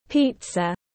Bánh pi-da tiếng anh gọi là pizza, phiên âm tiếng anh đọc là /ˈpiːtsə/
Pizza /ˈpiːtsə/